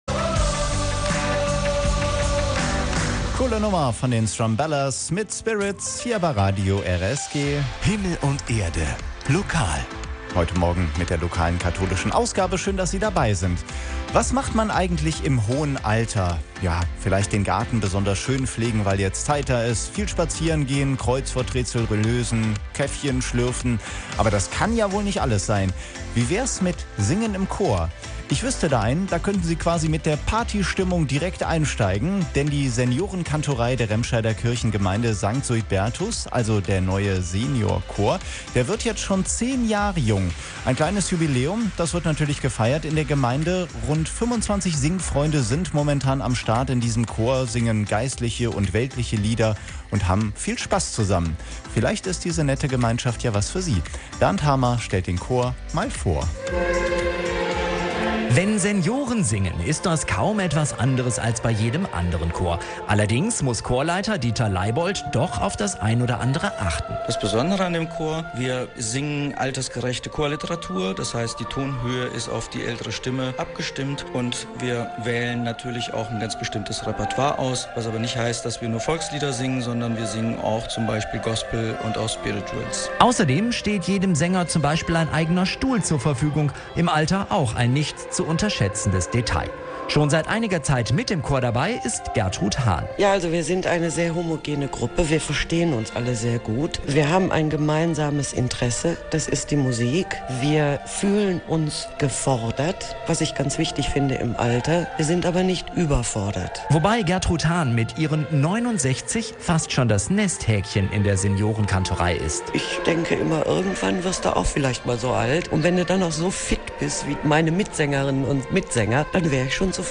Im Radio RSG wurde ein Beitrag zum Chorjubiläum gesendet |